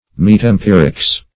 Search Result for " metempirics" : The Collaborative International Dictionary of English v.0.48: Metempirics \Met`em*pir"ics\, n. The concepts and relations which are conceived as beyond, and yet as related to, the knowledge gained by experience.